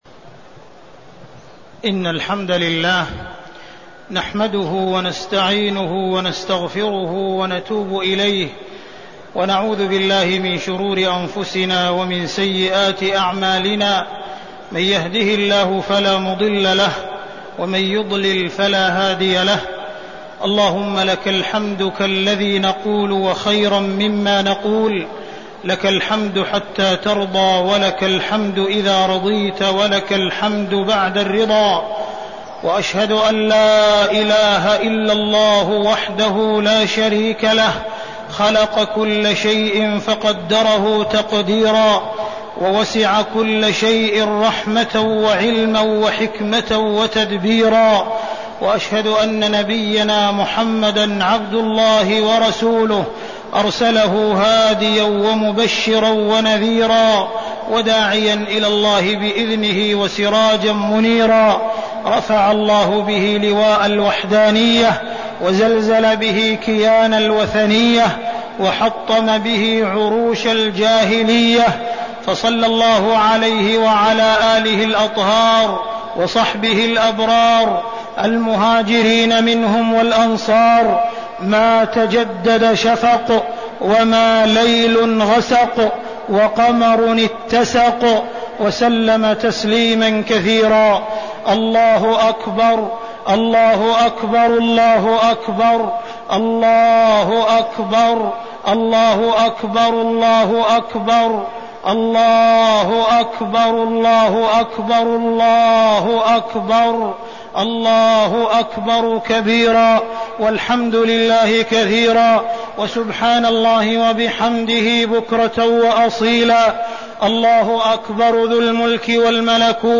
خطبة عيد الأضحى-قصة الفداء
تاريخ النشر ١٠ ذو الحجة ١٤١٦ هـ المكان: المسجد الحرام الشيخ: معالي الشيخ أ.د. عبدالرحمن بن عبدالعزيز السديس معالي الشيخ أ.د. عبدالرحمن بن عبدالعزيز السديس خطبة عيد الأضحى-قصة الفداء